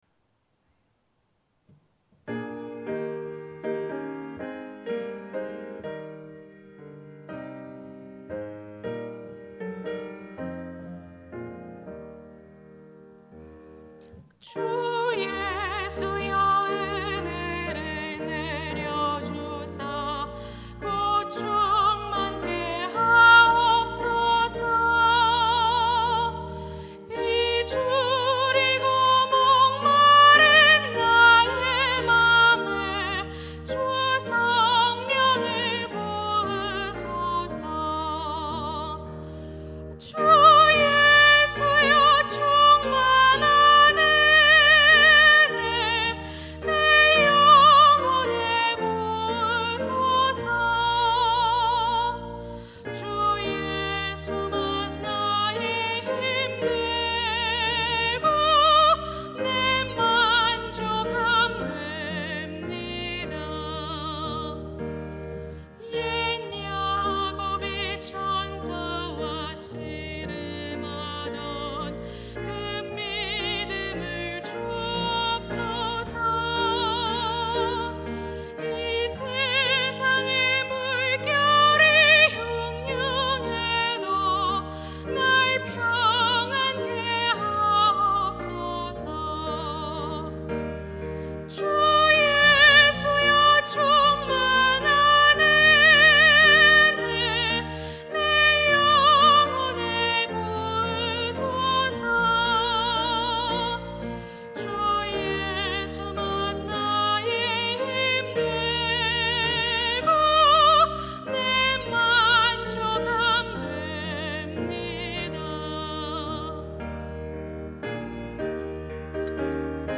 임마누엘